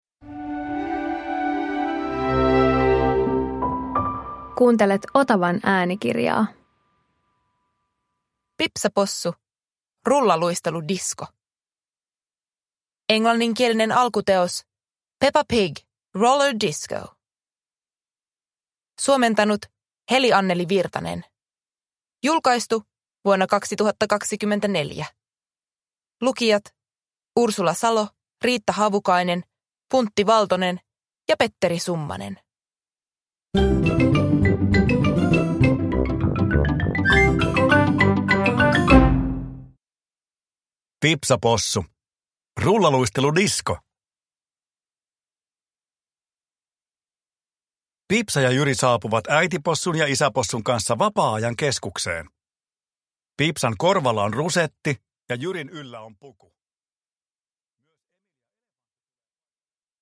Pipsa Possu - Rullaluisteludisko – Ljudbok